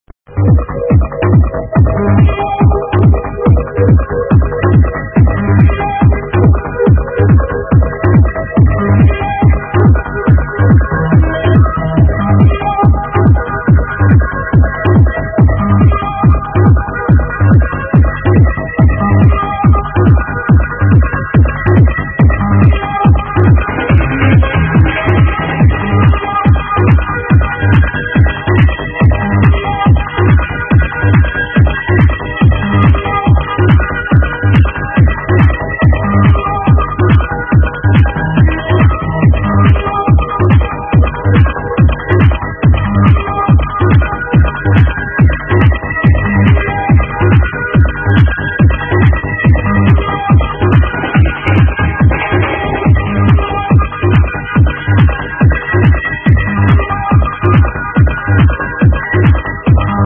This is pretty much my fave trance tune ever - thanks